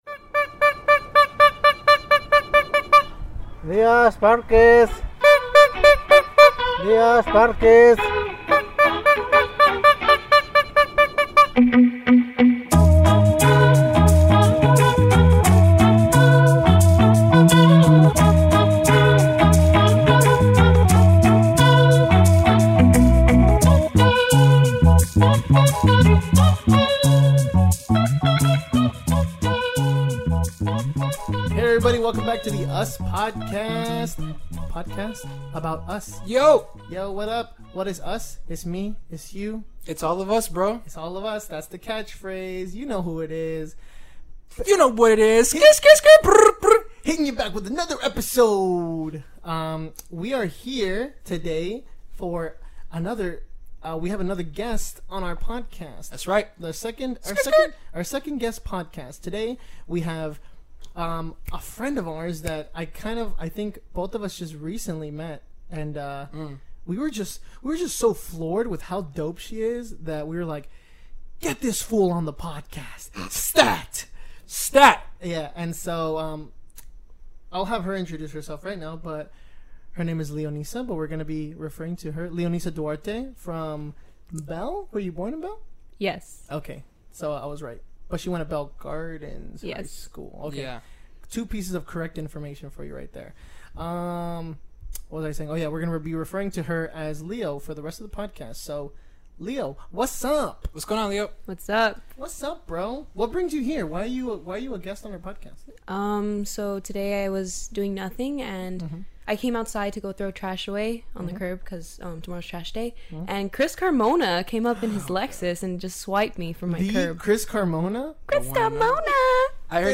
The Ustlers enjoy a lighthearted chat with a promising young filmmaker.